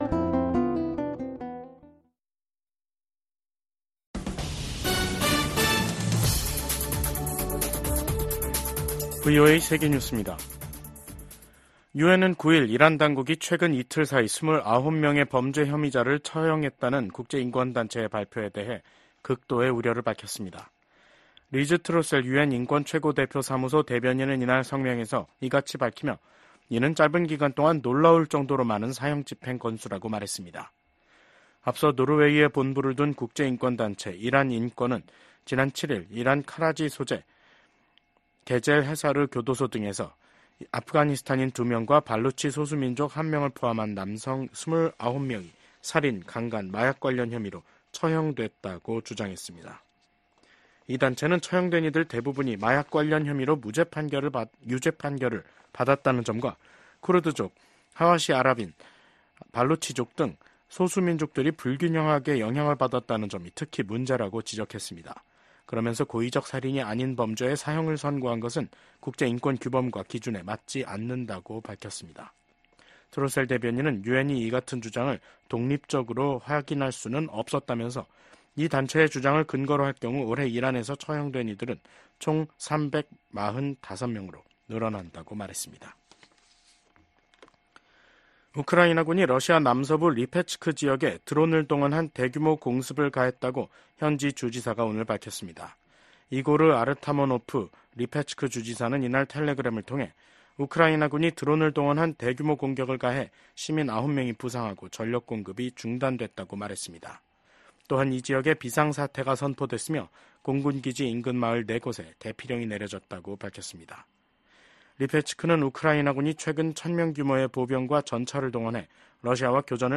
VOA 한국어 간판 뉴스 프로그램 '뉴스 투데이', 2024년 8월 9일 3부 방송입니다. 미국 국방부가 신형 미사일 발사대 전방 배치 같은 북한의 행동들은 역내 긴장을 고조시킨다고 지적했습니다. 미국이 한국과 일본 등 역내 동맹과의 협력 강화는 북한 위협 등에 대응하기 위한 방어적 성격이라고 강조했습니다.